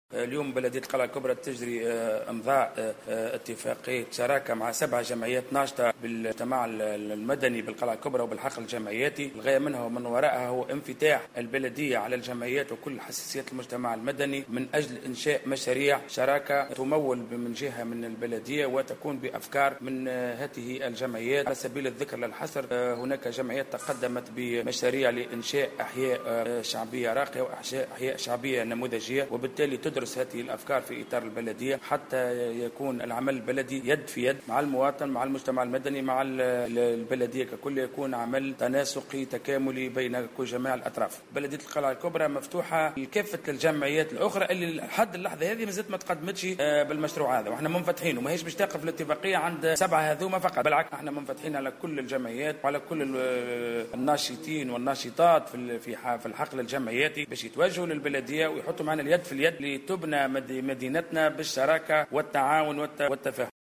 وأكد معتمد القلعة الكبرى ورئيس نيابتها الخصوصية، سعيد الماجري، في تصريح للجوهرة أف أم، أن هذا المشروع يندرج في إطار إنفتاح بلدية القلعة الكبرى على كافة أطياف وحساسيات المجتمع المدني، تهدف إلى إنجاز مشاريع من تصوّر واقتراح هذه الجمعيات وتموّل من قبل البلدية، داعيا بقية الجمعيات في الجهة إلى الانخراط في هذه الاتفاقيات.